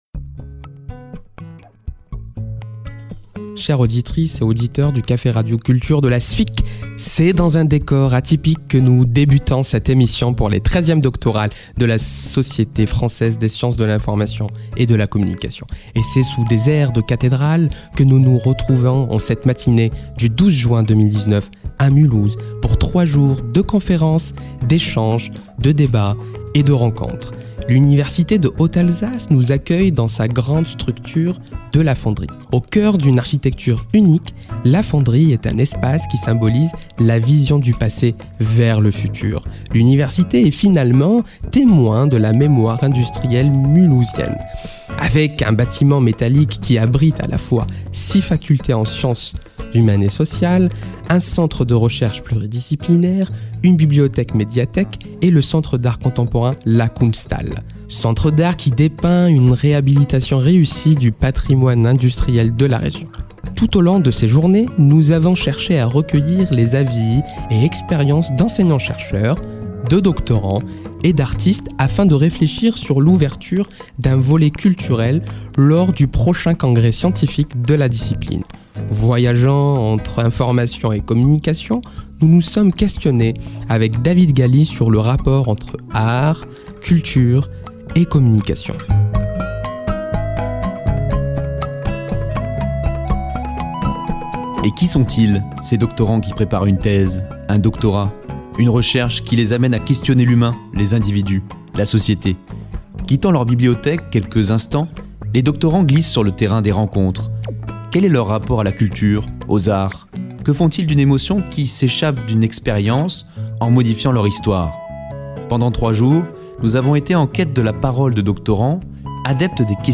Le « Café-Radio-Culture » est une émission radiophonique sur le thème de la culture, enregistrée dans le cadre d’événements scientifiques, lors des moments de pause-café.